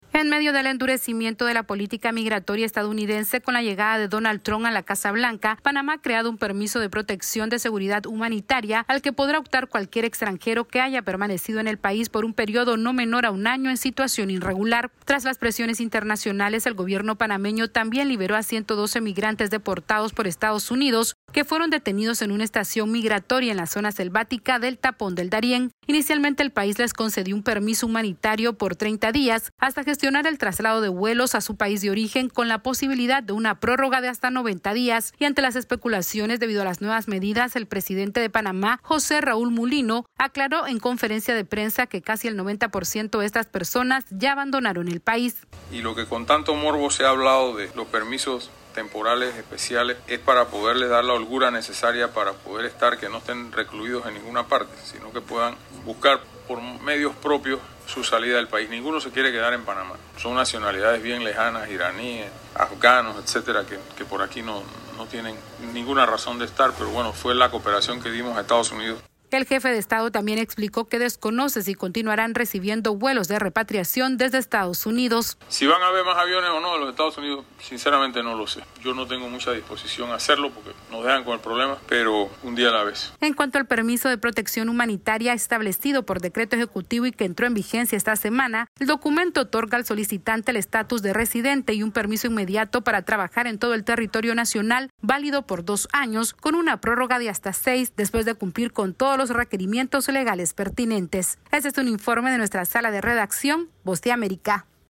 Panamá creó un permiso de protección humanitaria que permite a los migrantes irregulares permanecer hasta por 8 años en el país y anunció la salida de casi el 90% de los migrantes repatriados por Estados Unidos. Este es un informe de nuestra Sala de Redacción de la Voz de América.